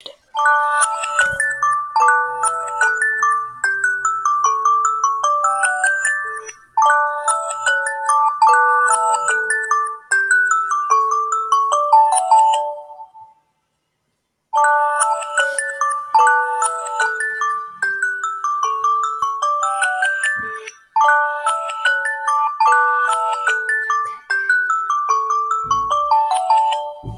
Samsung Alarm. Sound Button - Free Download & Play